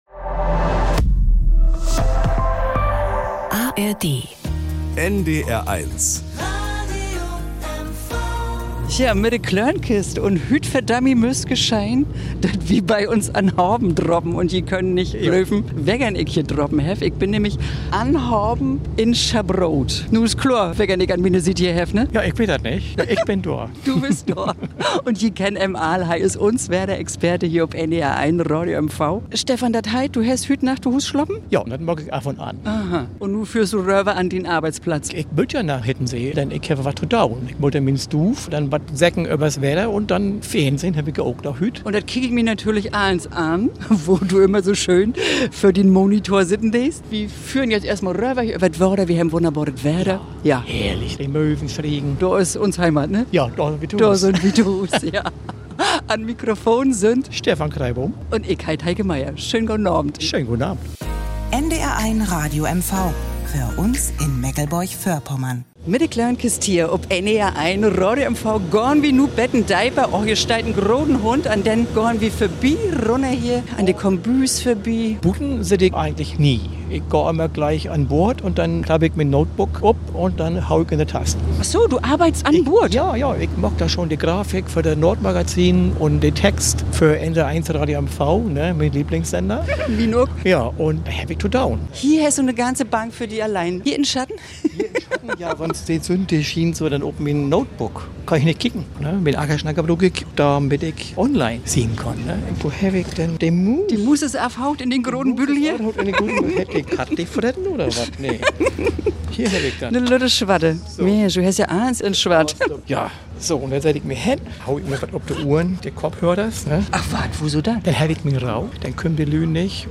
schon auf dem Weg zum Arbeitsplatz, dann im Wetterstudio